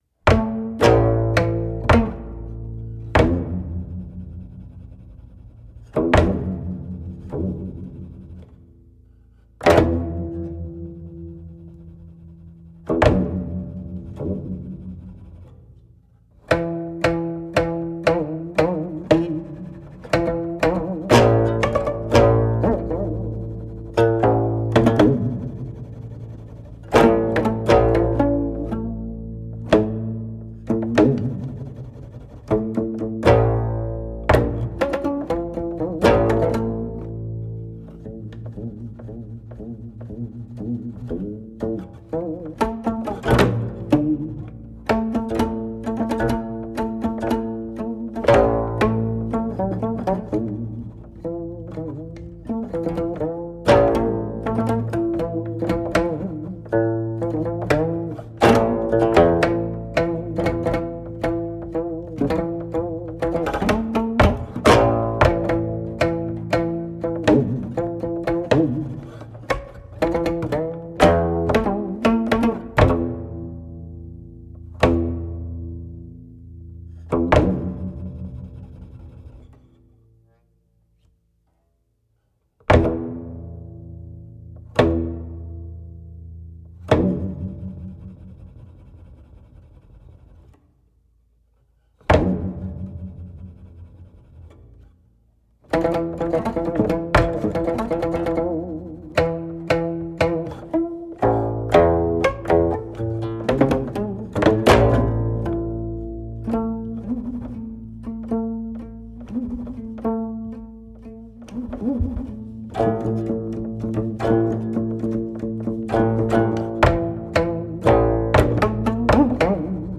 カヤグム（伽耶琴）とコムンゴ（玄琴）の音源集！